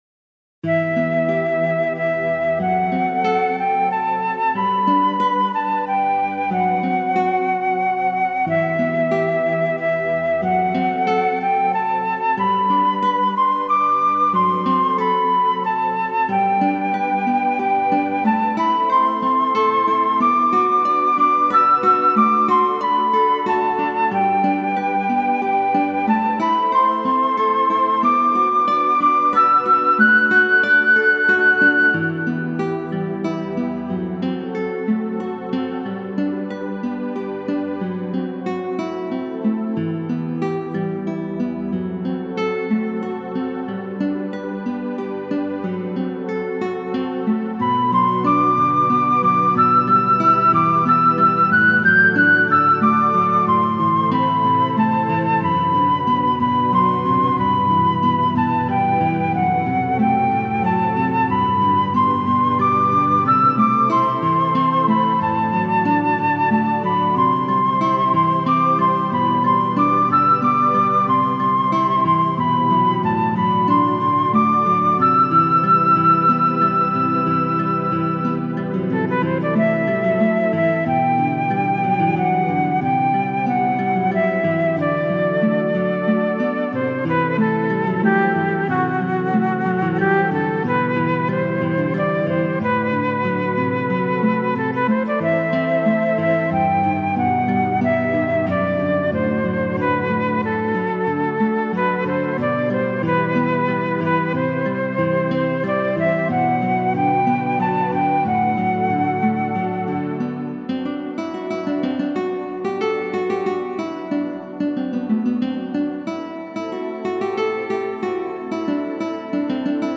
上記2曲と同じく「ファンタジック・トラッド」として制作を始め、スケッチしていたその中の1曲。
夕暮れの物寂しさをイメージした曲です。 フルートとギターは琴線に触れるものがありますね。